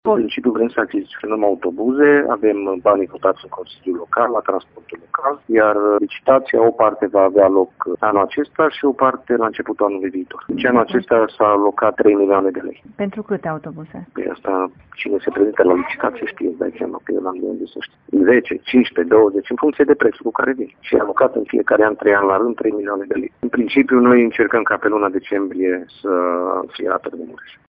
Depinde, însă, și de durata procedurii de licitație, spune viceprimarul Claudiu Maior, care nu știe exact câte vor fi achiziționate: